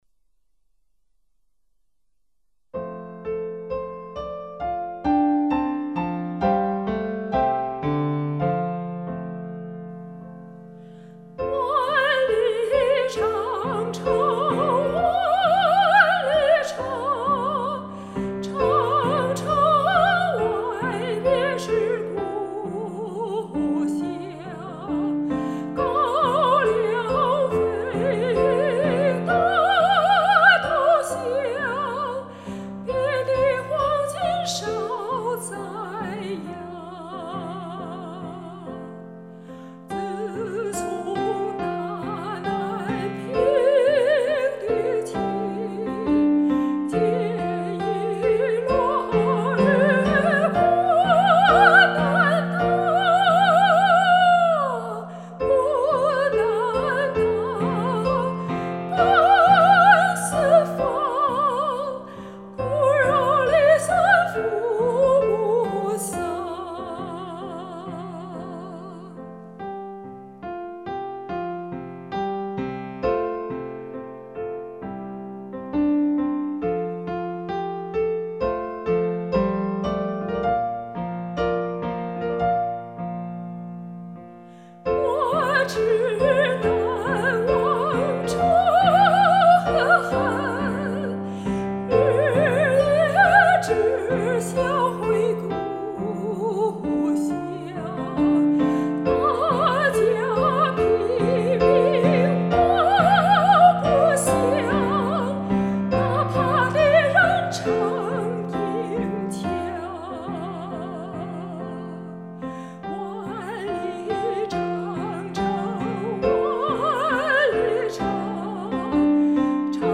第三句听着不对劲。